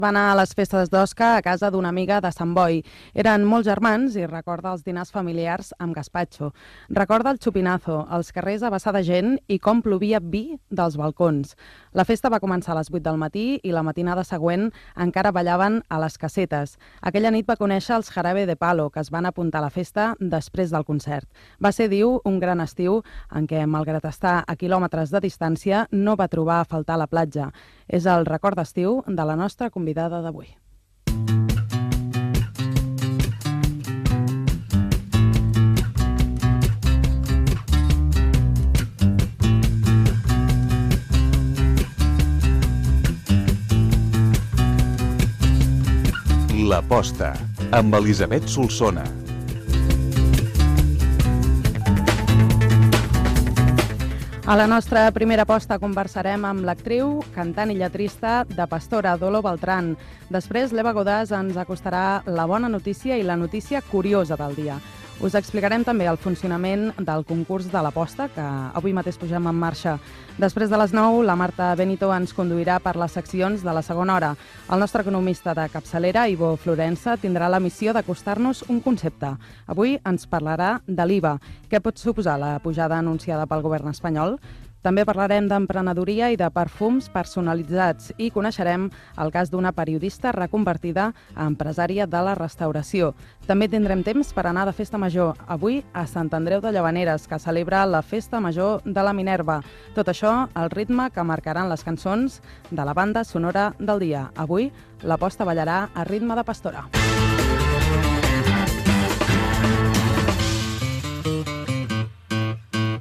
Record d'estiu de la convidada al programa (Dolo Beltran), careta i sumari
Entreteniment
Fragment extret de l'arxiu sonor de COM Ràdio.